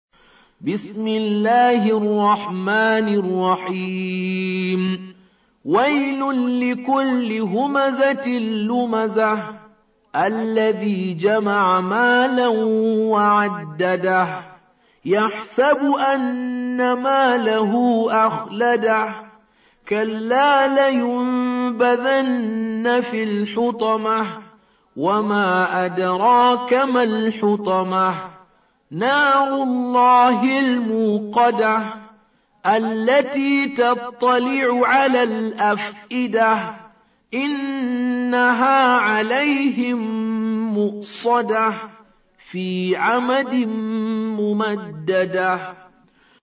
104. Surah Al-Humazah سورة الهمزة Audio Quran Tarteel Recitation